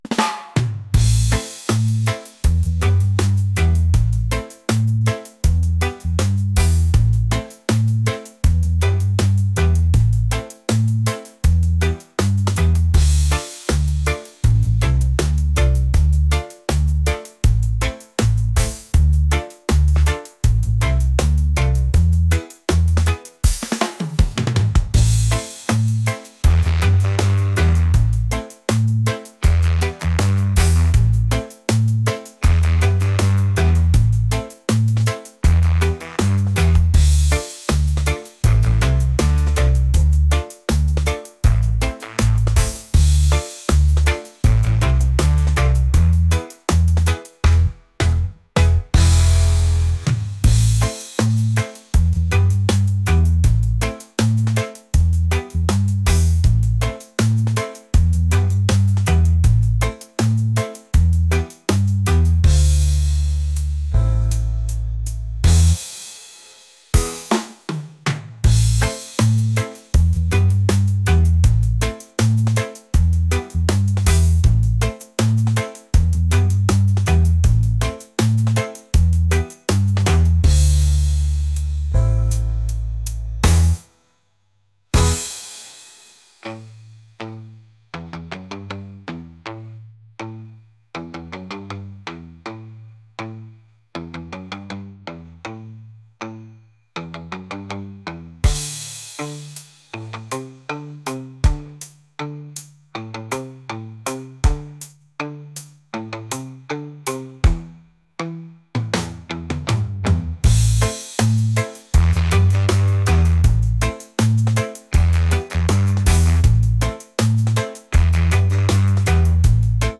reggae | lounge | lofi & chill beats